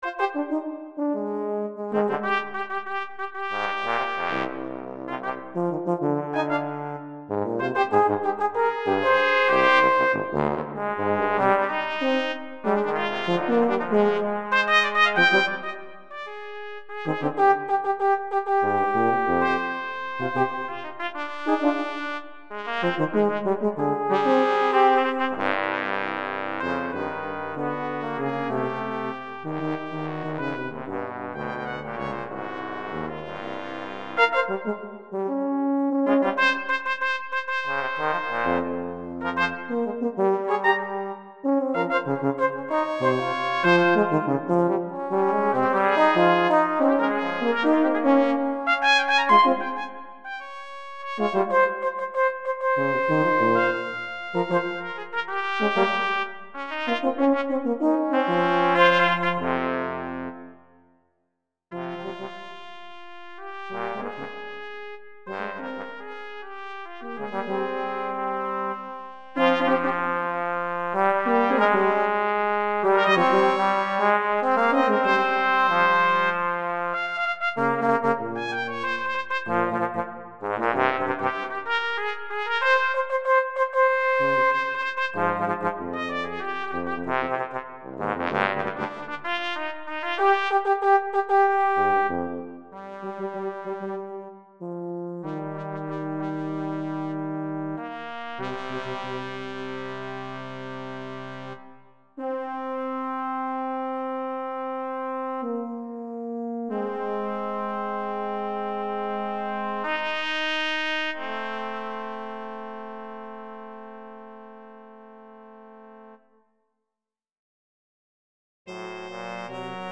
Brazen Overtures (trumpet, french horn, and tuba) – 2006